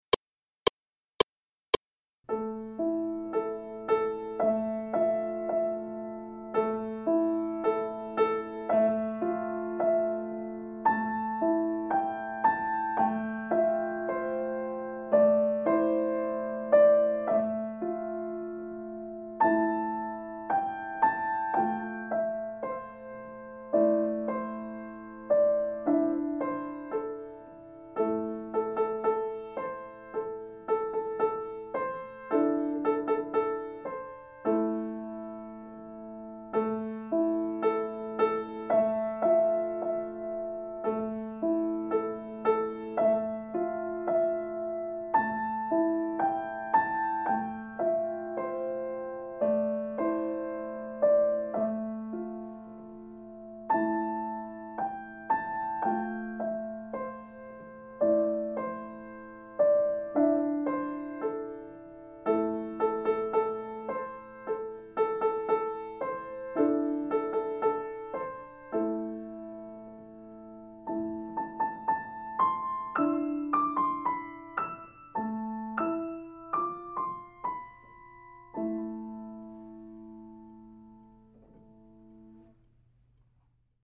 반주